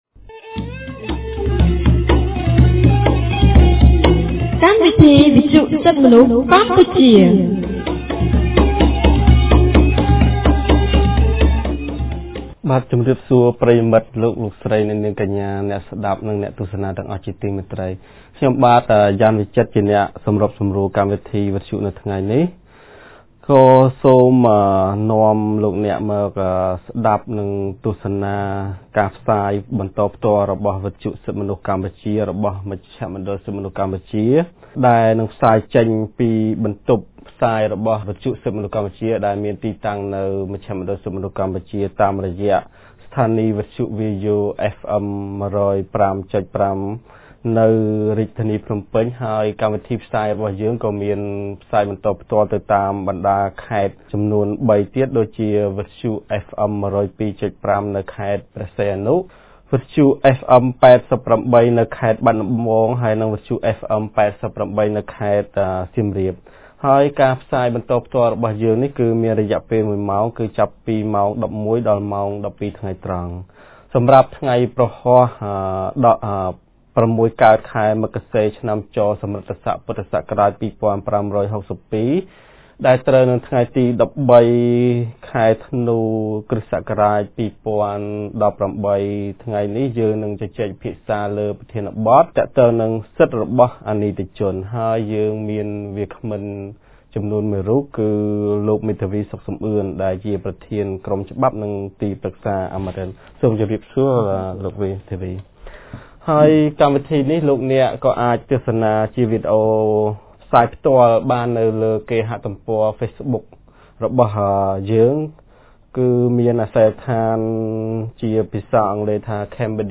On 13 December 2018, CCHR’s Fair Trial Rights Project (FTRP) held a radio program with a topic on Rights of juveniles.